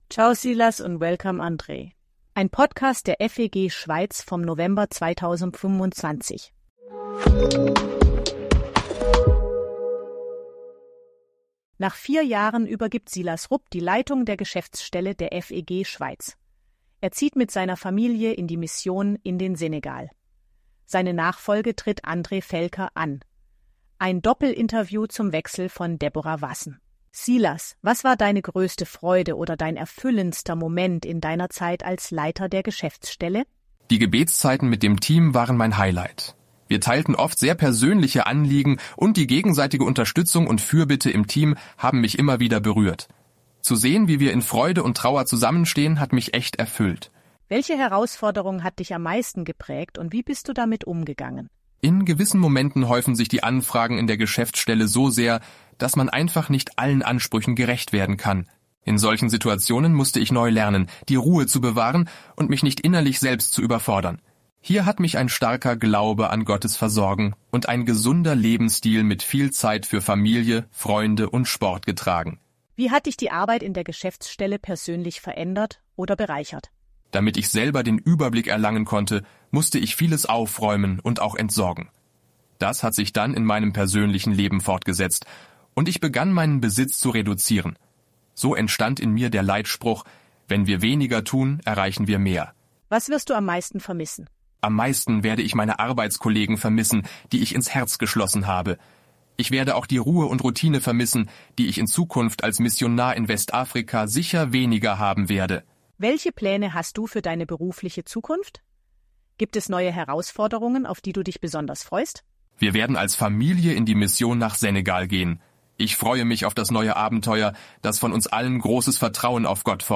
Doppel-Interview zum Wechsel.